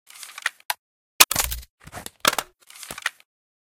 gauss_reload.ogg